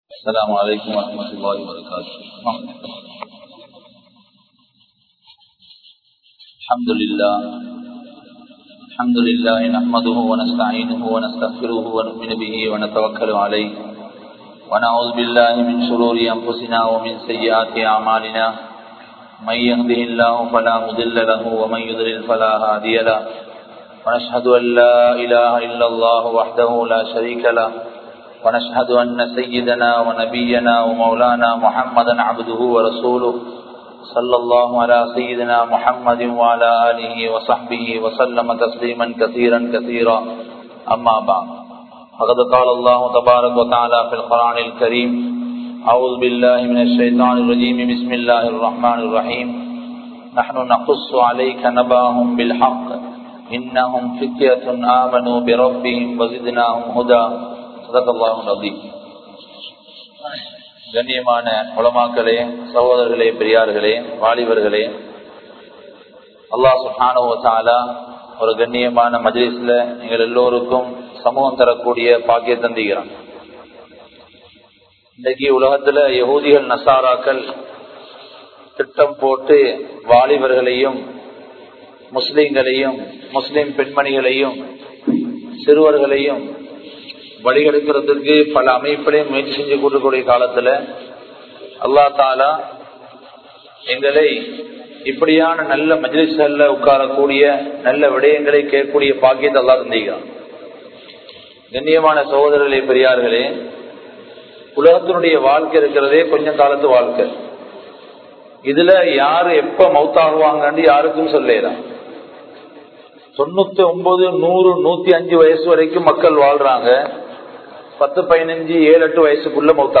The Importance of Youth | Audio Bayans | All Ceylon Muslim Youth Community | Addalaichenai